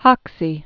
(hŏksē), Vinnie Ream 1847-1914.